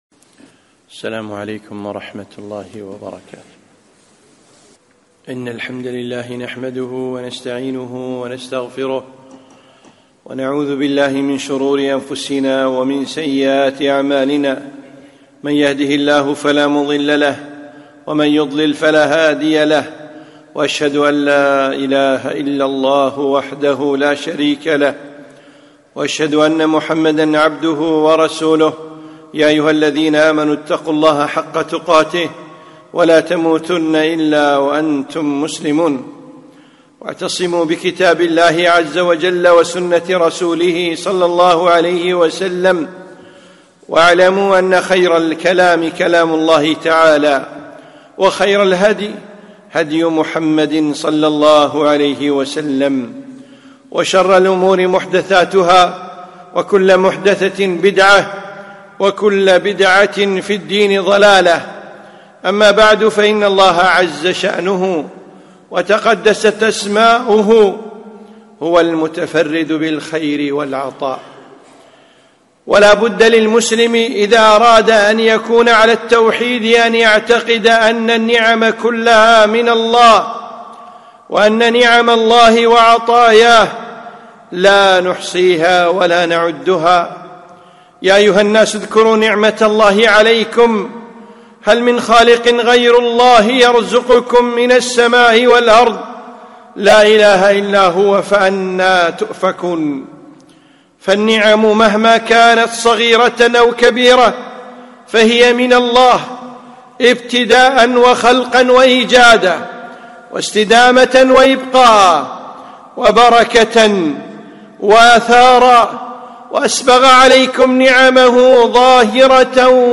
خطبة - شكر الله